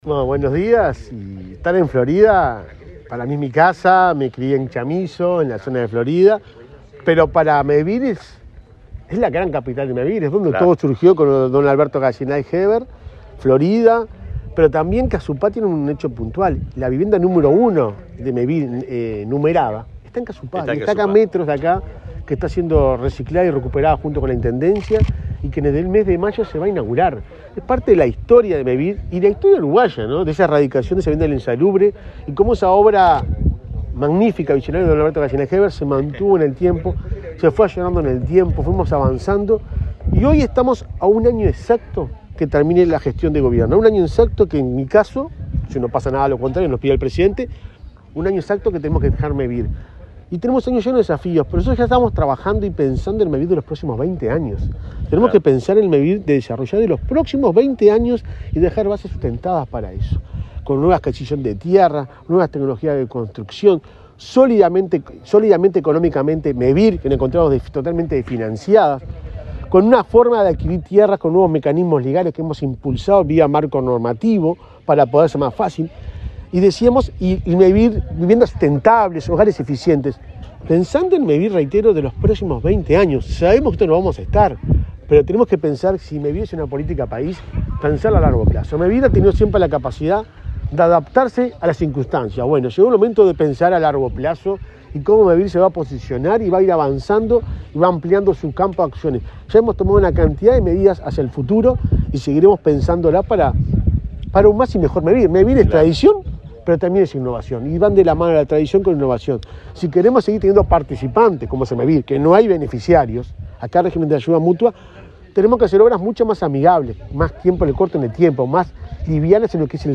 Declaraciones del presidente de Mevir, Juan Pablo Delgado
El presidente de Mevir, Juan Pablo Delgado, dialogó con la prensa en Florida, antes de inaugurar 56 viviendas en la localidad de Casupá.